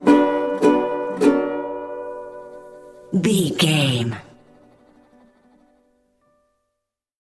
Uplifting
Ionian/Major
D
acoustic guitar
bass guitar